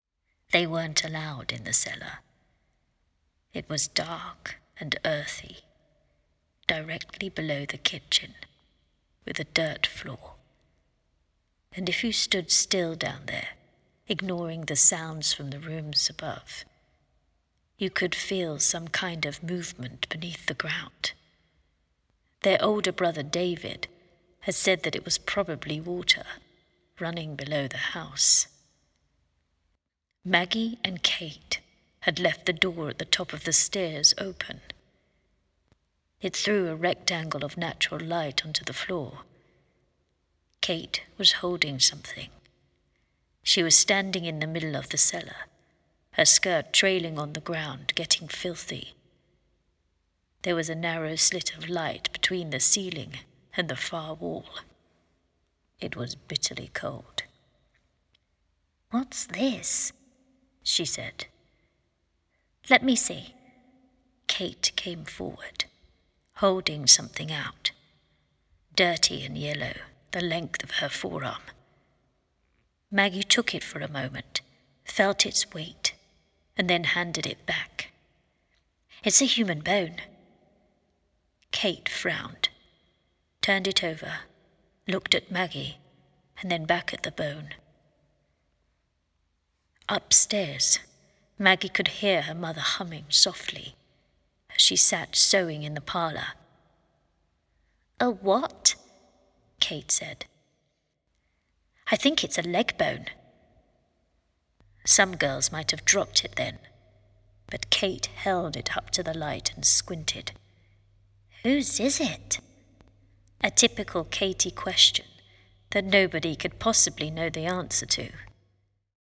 Audiobook Reel
• Native Accent: London
Fresh, crisp and youthful
Based in London with a great home studio.